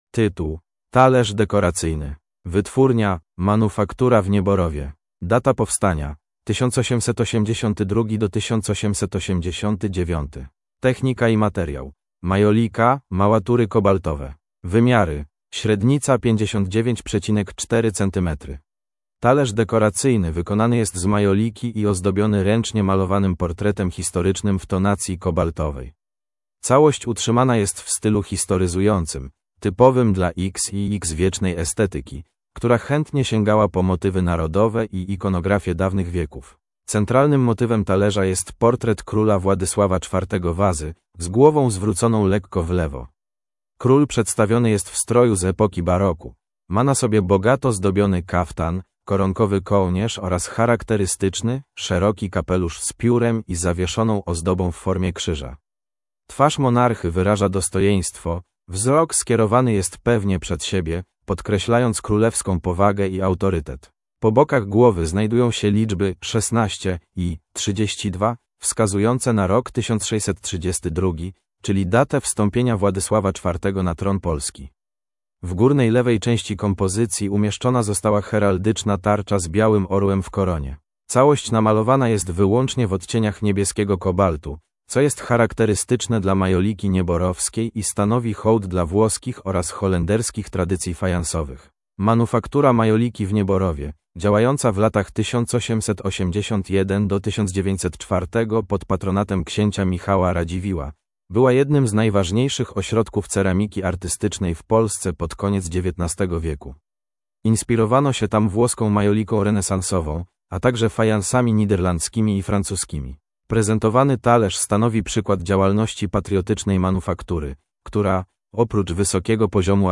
MNWr_audiodeskr_Talerz_dekoracyjny.mp3